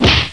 SLAP.mp3